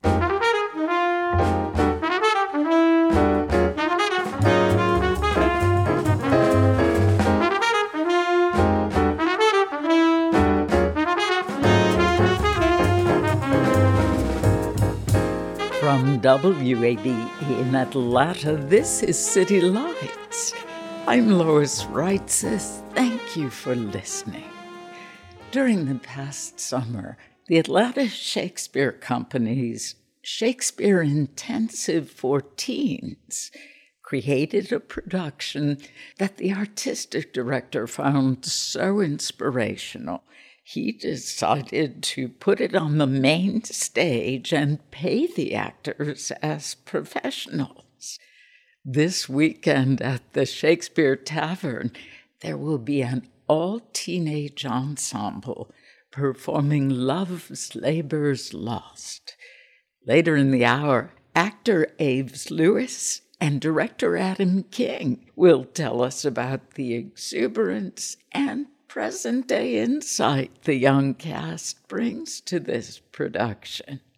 captured from the web stream